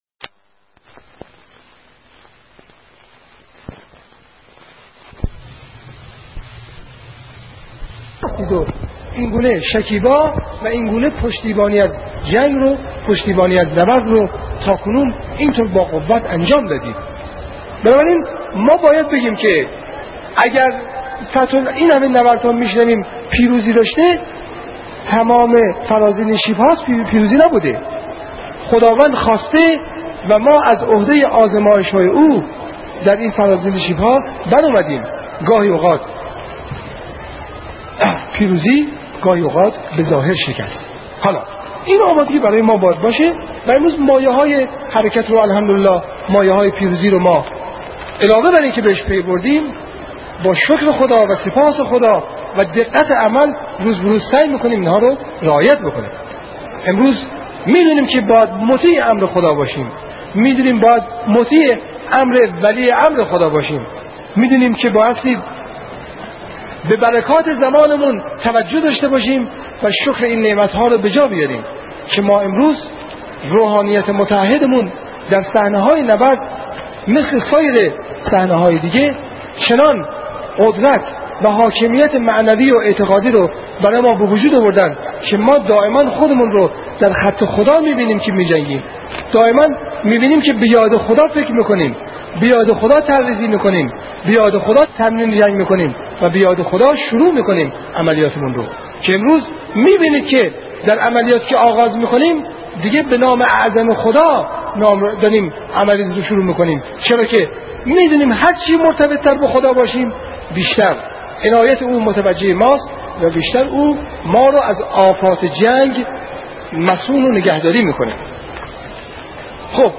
شهید علی صیادشیرازی طی سخنانی برای رزمندگان اسلام توکل و توسل را عامل پیروزی عنوان می‌کند و از دلایل پیروزی ملت ایران در دفاع مقدس می‌گوید.